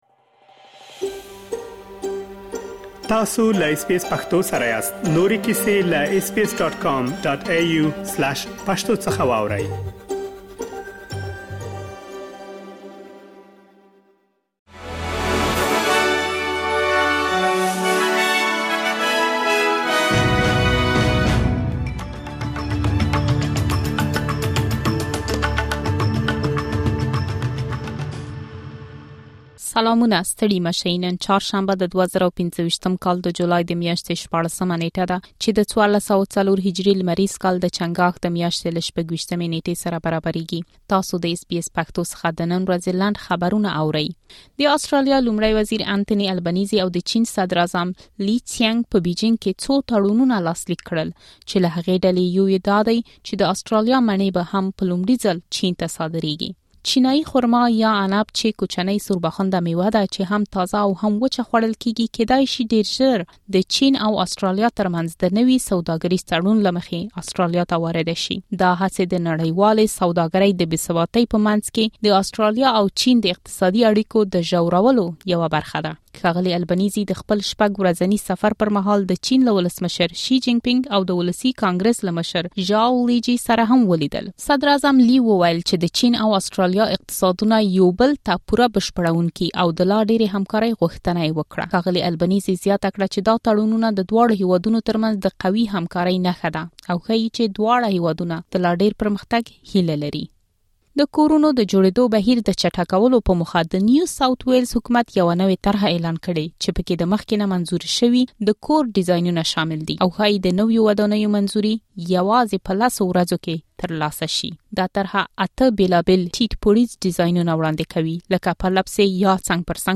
د اس بي اس پښتو د نن ورځې لنډ خبرونه |۱۶ جولای ۲۰۲۵